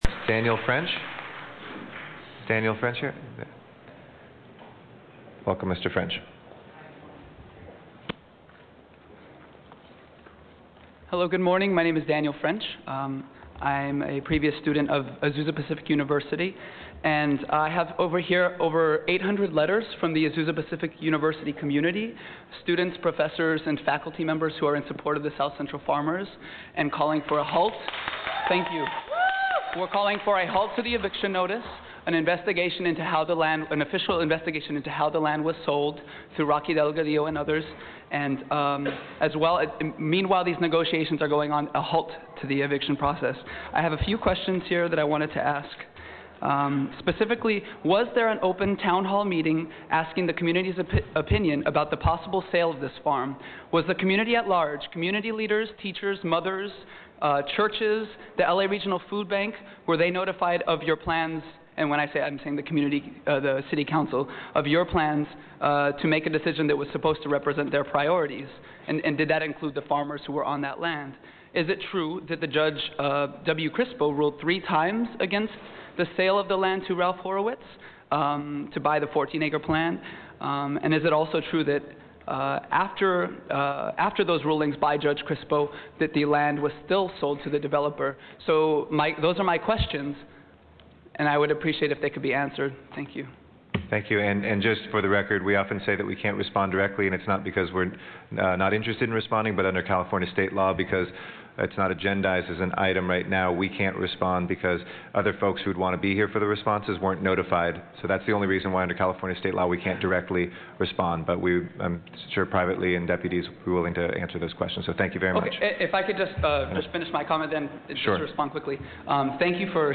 LOS ANGELES, March 17, 2006 - Farmers and their supporters addressed Friday’s session of the Los Angeles City Council during the public comment section as they have been doing every Friday for the past three years. Yet today was different for the issue of the South Central Farm was added as an agenda item on today’s council’s session.